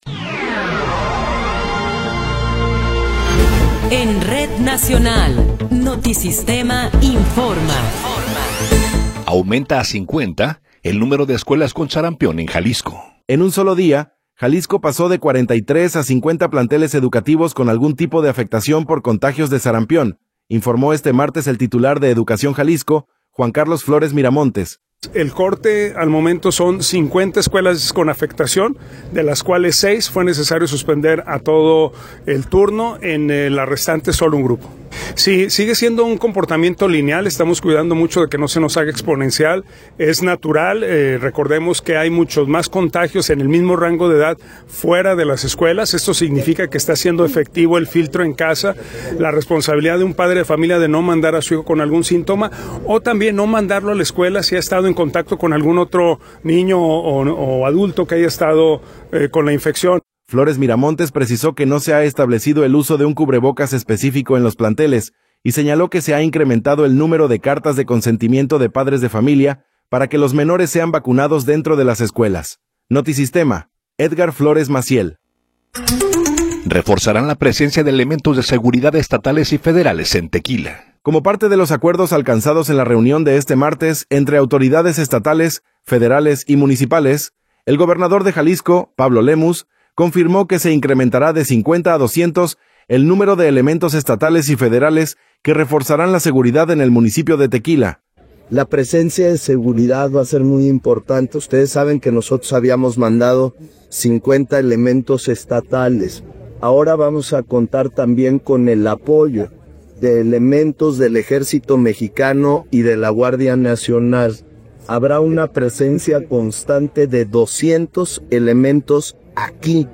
Noticiero 16 hrs. – 10 de Febrero de 2026